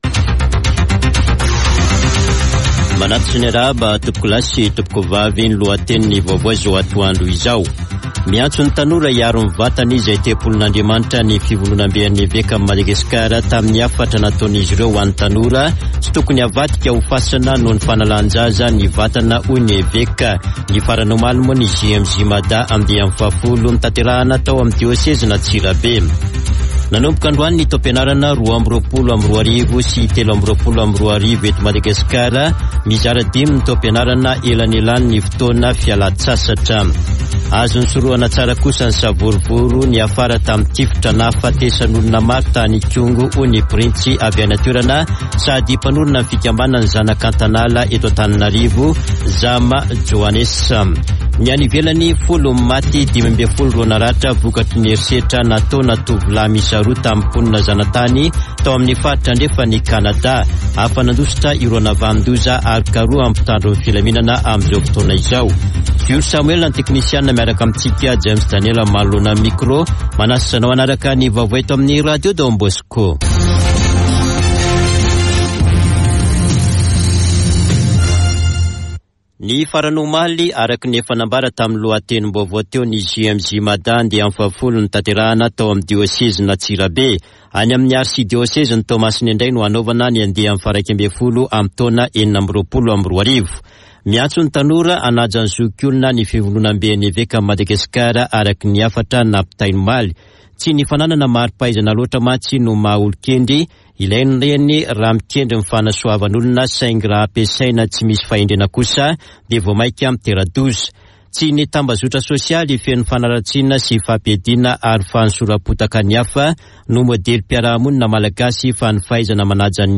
[Vaovao antoandro] alatsinainy 05 septambra 2022